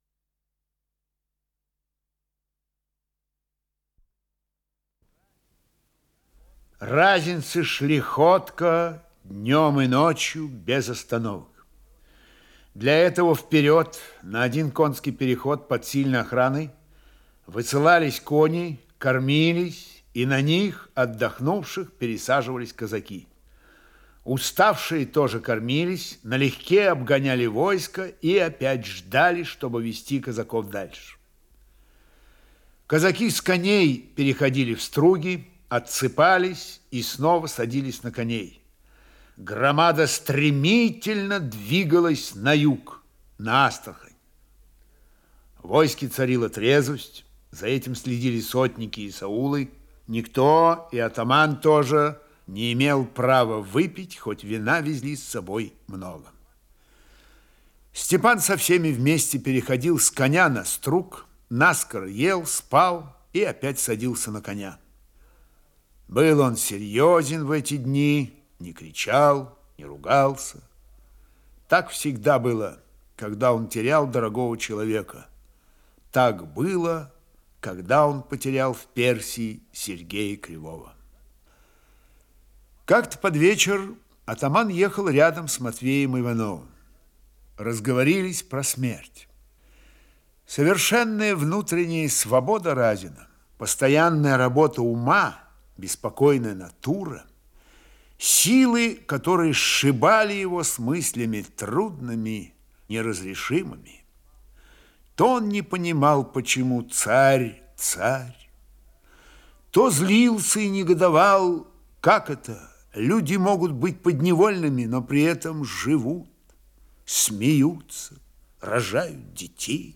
Исполнитель: Михаил Ульянов - чтение